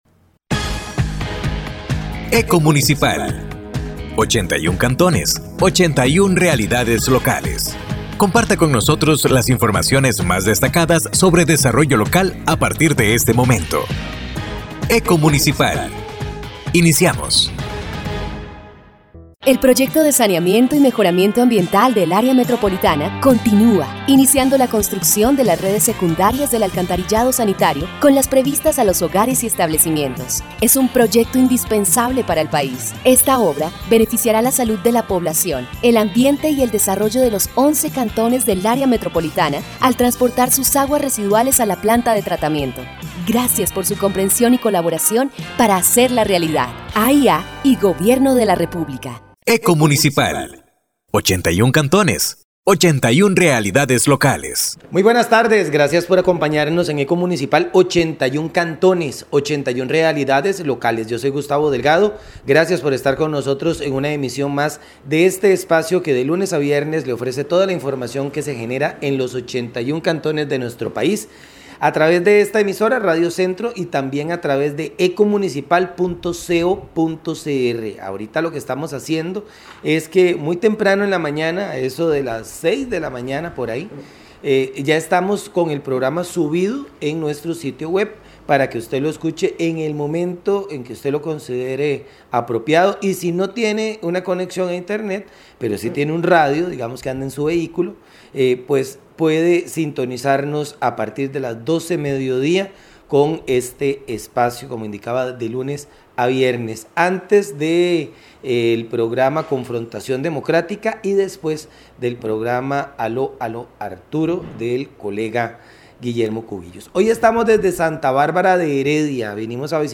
Programa de Radio Eco Municipal